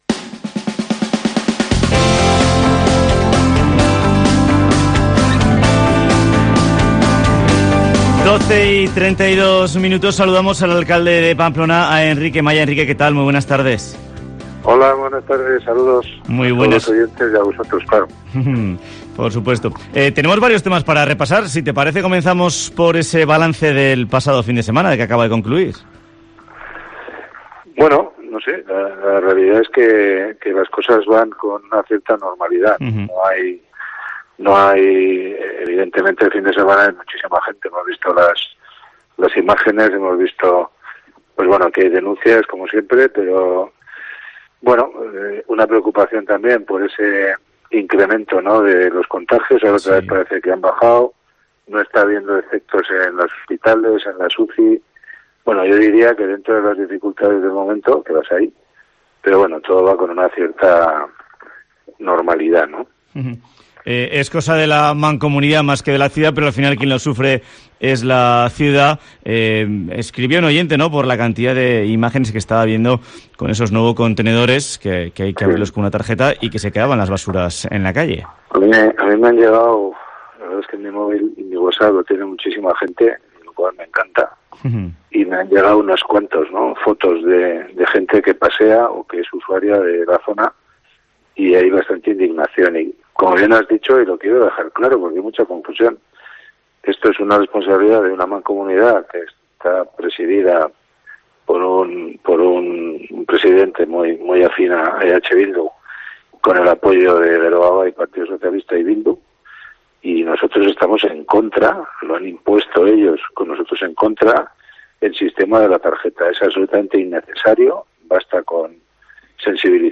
Entrevista con Enrique Maya, alcalde de Pamplona